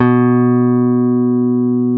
guitar nș 137
guitar137.mp3